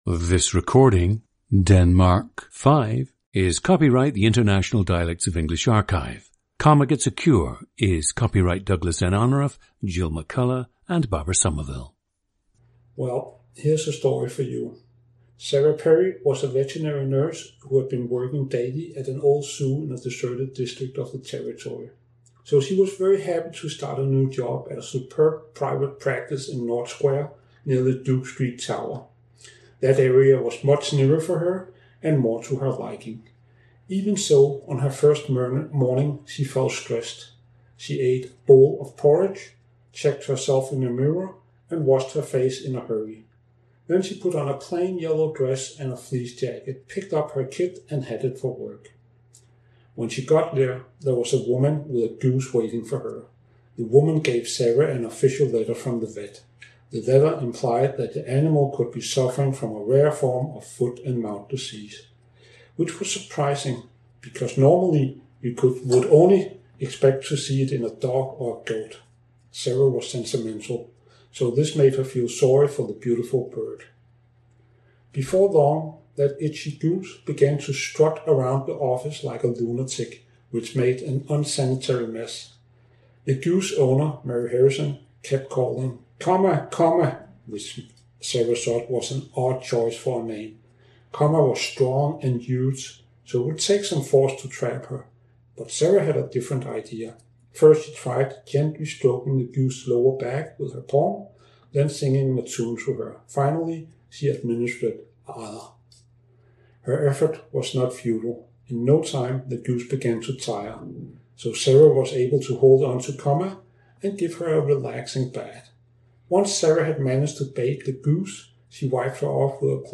GENDER: male
The speaker’s native dialect is “Rigsdansk” or the standard variety of Danish that one usually hears in Copenhagen.
• Recordings of accent/dialect speakers from the region you select.
The recordings average four minutes in length and feature both the reading of one of two standard passages, and some unscripted speech.